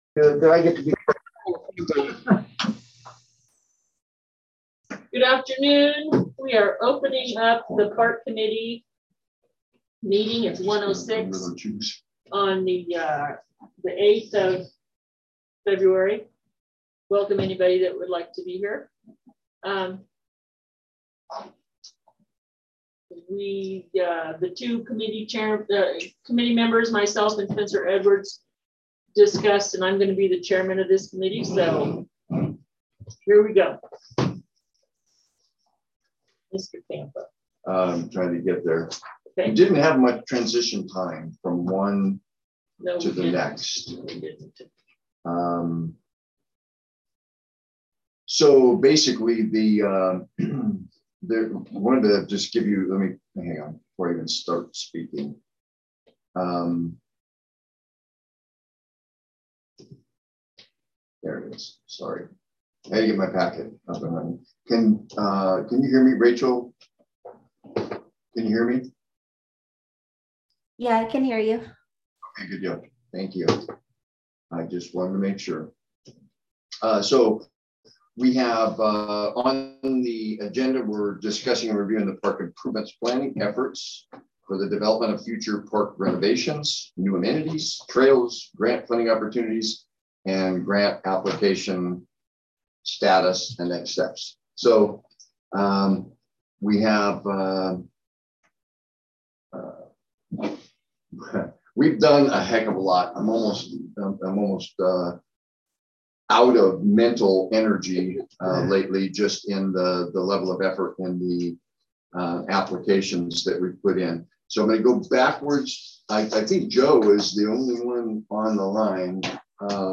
Park Committee Meeting